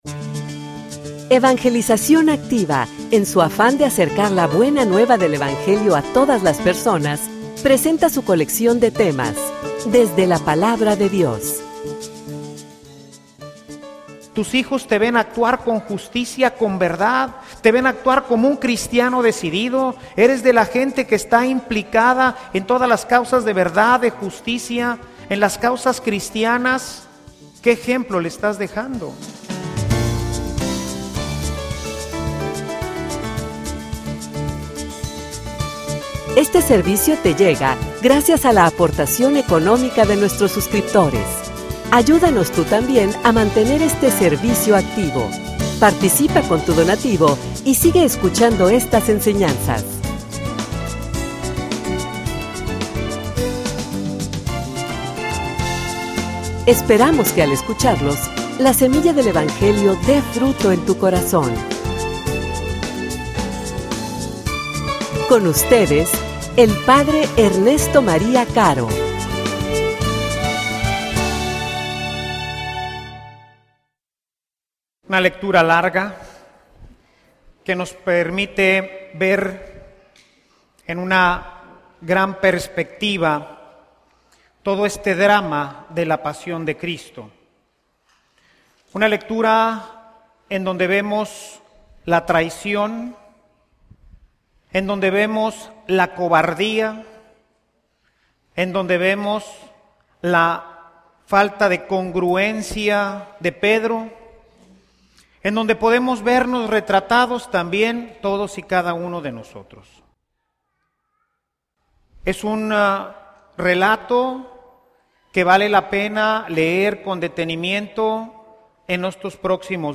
homilia_Darias_la_vida_por_El.mp3